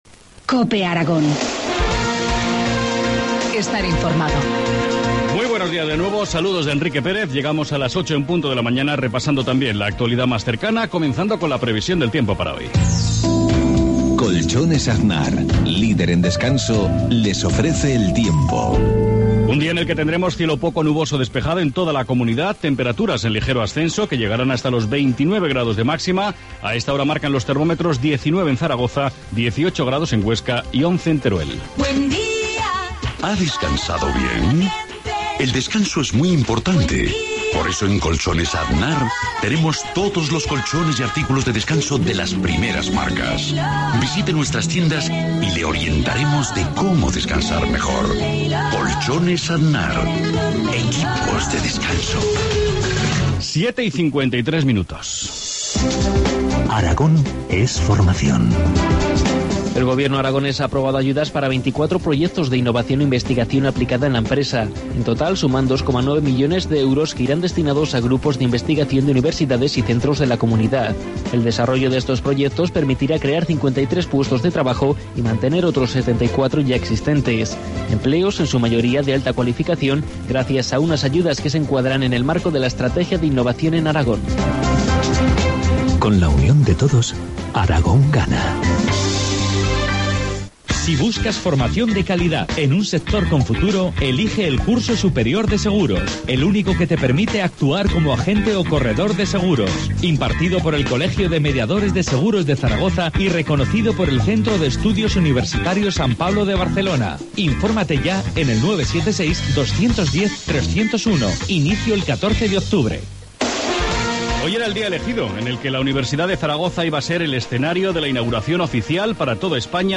Informativo matinal, lunes 23 septiembre, 2013, 7,53 horas